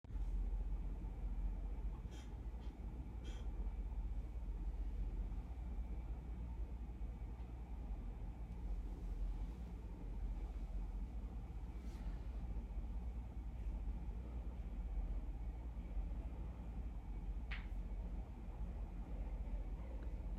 backnoise (3).wav